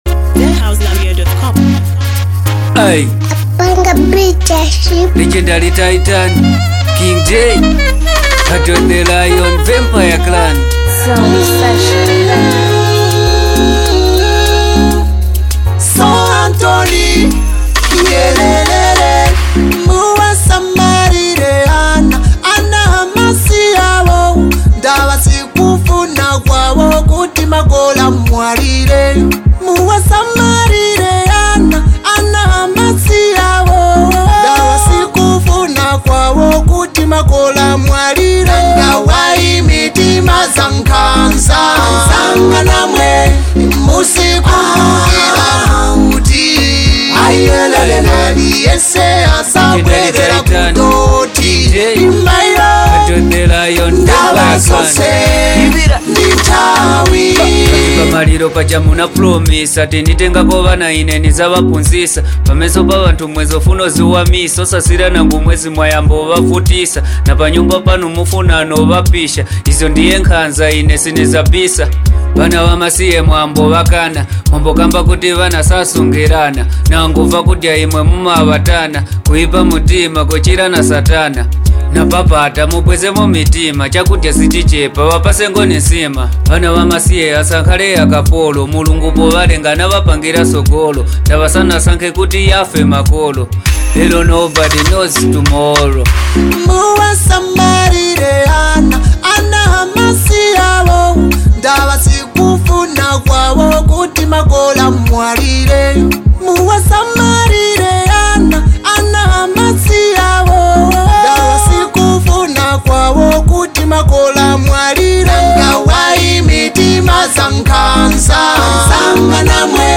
heartfelt new single